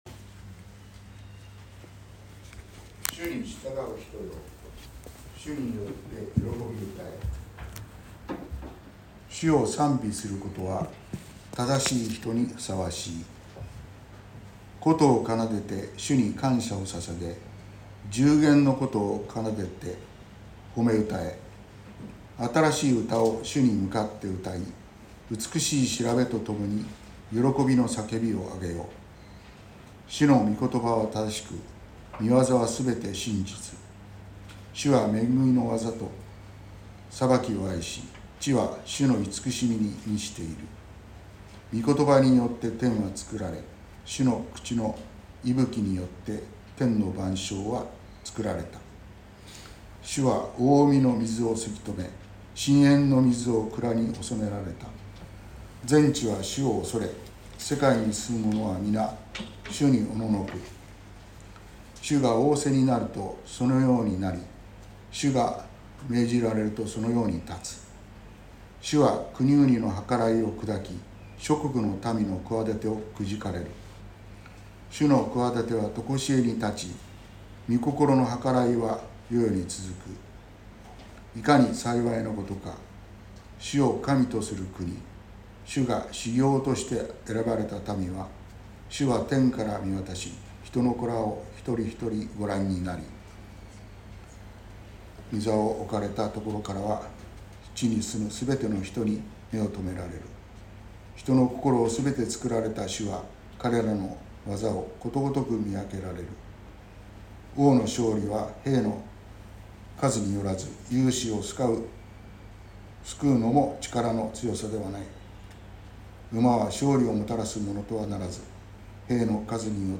2023年05月28日朝の礼拝「ｷﾘｽﾄと共に生きる喜び」川越教会
川越教会。説教アーカイブ。